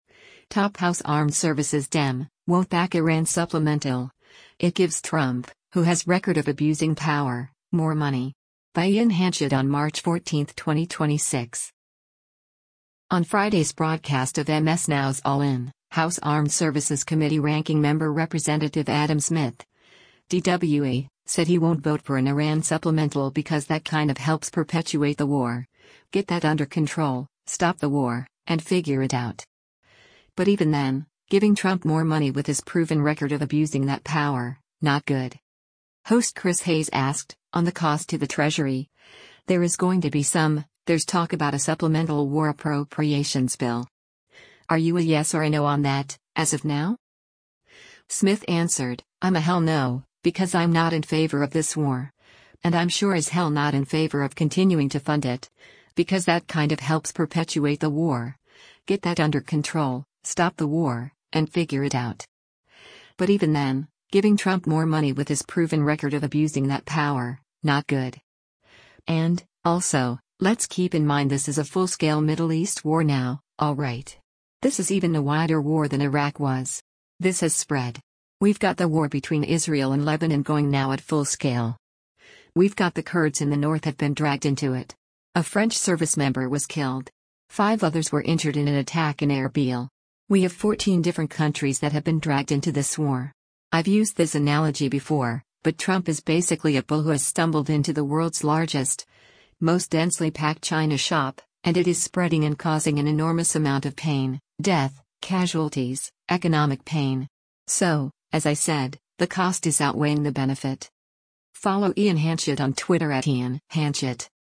On Friday’s broadcast of MS NOW’s “All In,” House Armed Services Committee Ranking Member Rep. Adam Smith (D-WA) said he won’t vote for an Iran supplemental “because that kind of helps perpetuate the war, get that under control, stop the war, and figure it out. But even then, giving Trump more money with his proven record of abusing that power, not good.”
Host Chris Hayes asked, “On the cost to the Treasury, there is going to be some — there’s talk about a supplemental war appropriations bill. Are you a yes or a no on that, as of now?”